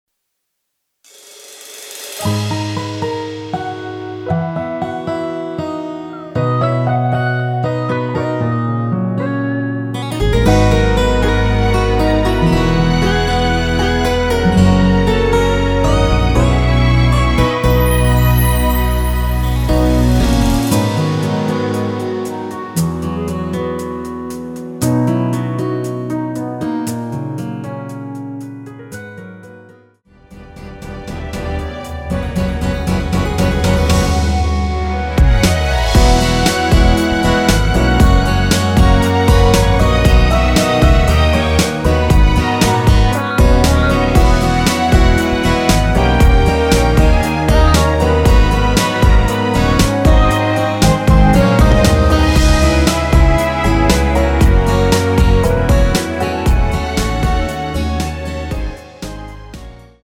원키에서(-1)내린 멜로디 포함된 MR입니다.(미리듣기 참조)
Abm
앞부분30초, 뒷부분30초씩 편집해서 올려 드리고 있습니다.
중간에 음이 끈어지고 다시 나오는 이유는